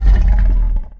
tb_growl_1.ogg